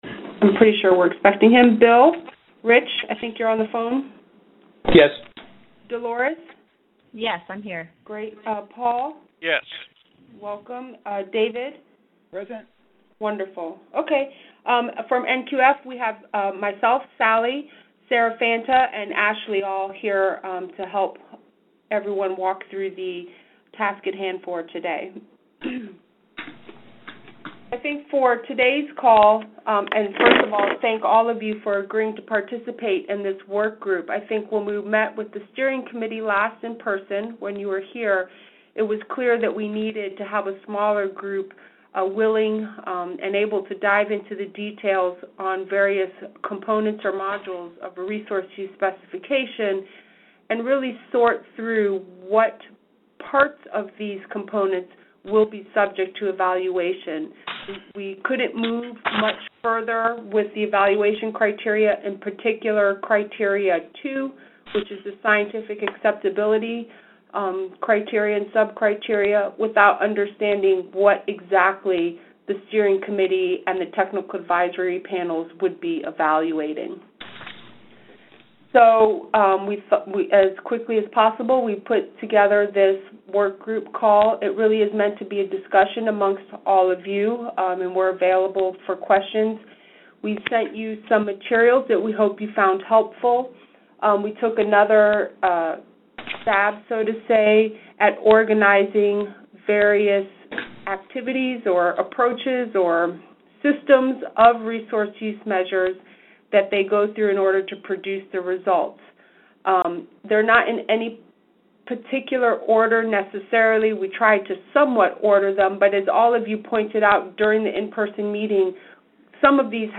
Meeting Recording
The Steering Committee met via conference call on October 20, 2010 to discuss the Resource Use Measurement White Paper comments and Resource Use Measure Evaluation Criteria.